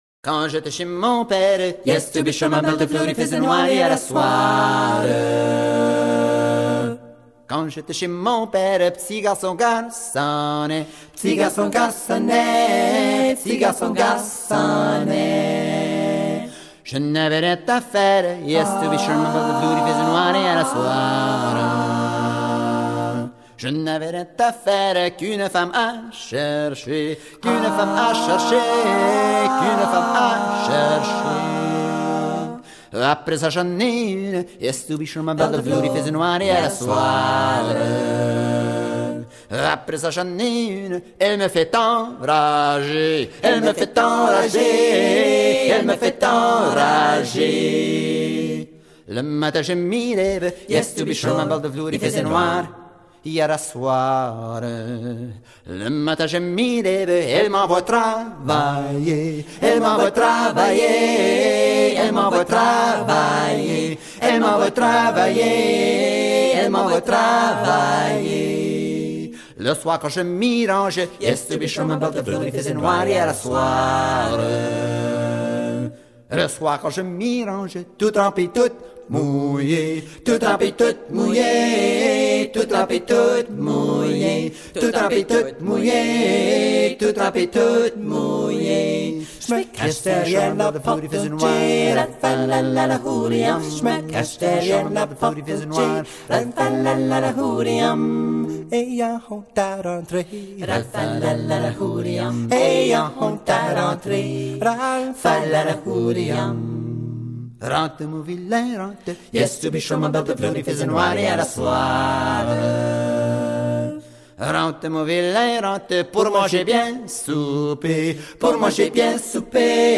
Barachois - Acadian Music & Dance Sensation